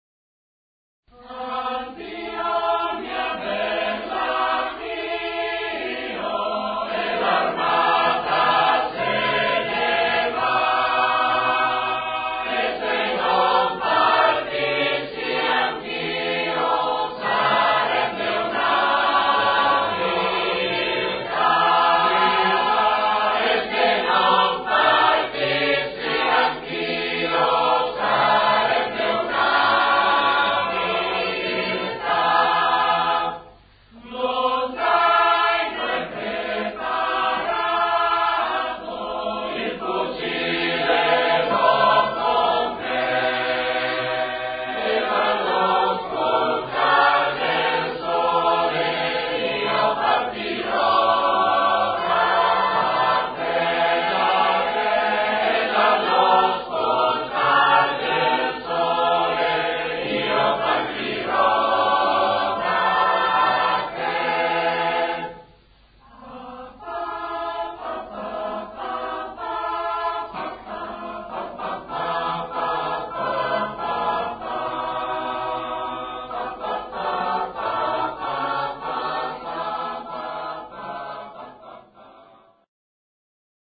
Addio mia bella addio [ voci miste ] Addio mia bella, addio Che l’armata se ne va E se non partissi anch’io Sarebbe una viltà.